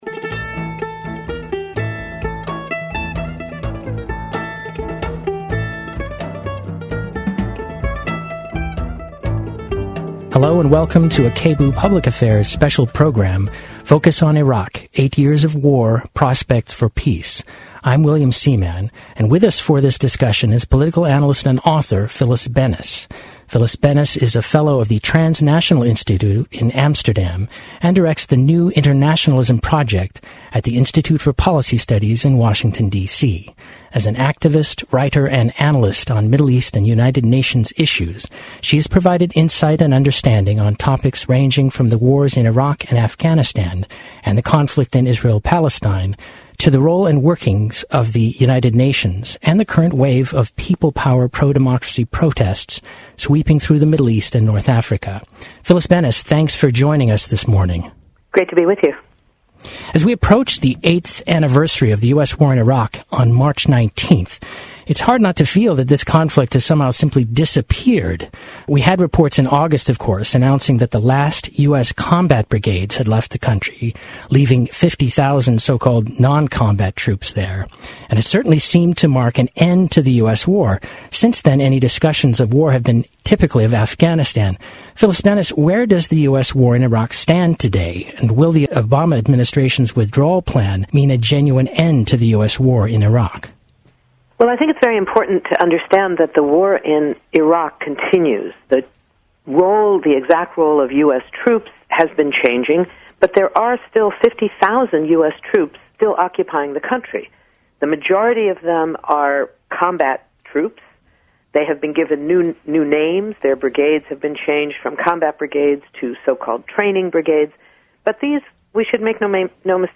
interviews Middle East analyst and author